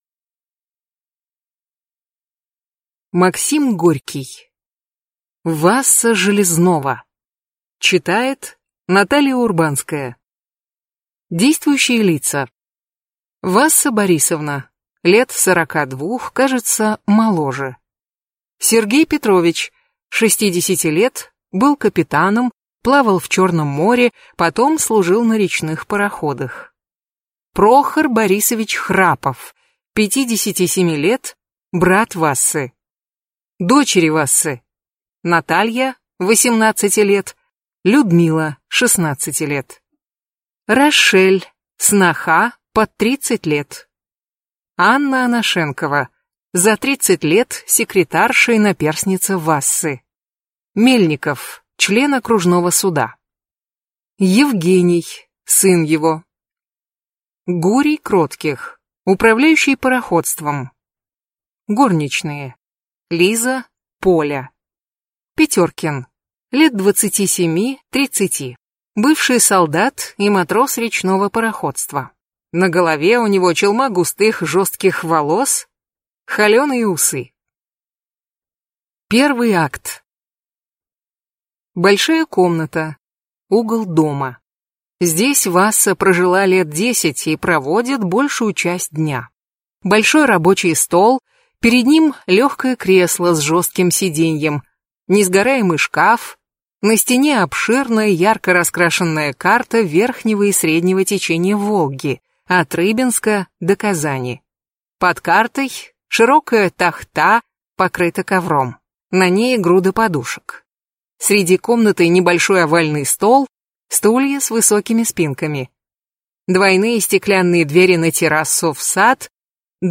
Аудиокнига Васса Железнова | Библиотека аудиокниг